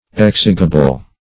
Search Result for " exigible" : The Collaborative International Dictionary of English v.0.48: Exigible \Ex"i*gi*ble\, a. [Cf. F. exigible.